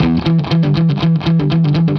Index of /musicradar/80s-heat-samples/120bpm
AM_HeroGuitar_120-E01.wav